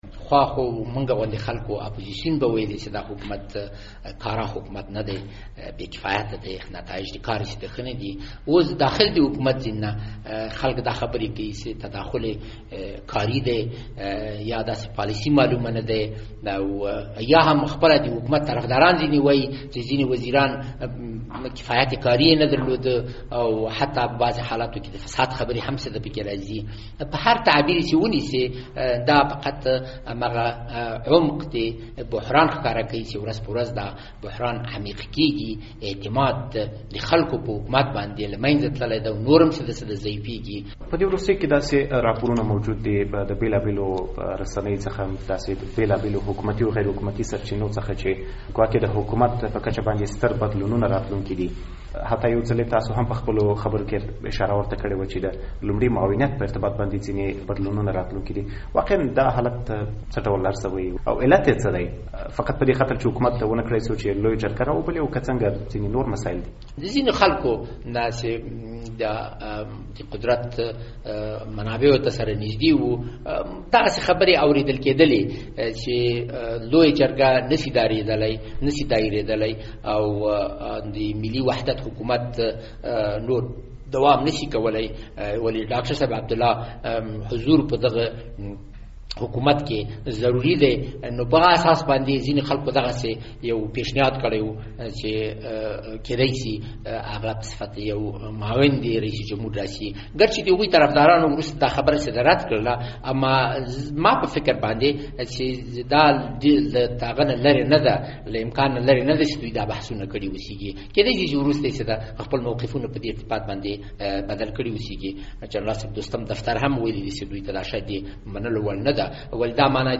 مرکه
له انوار الحق احدي سره مرکه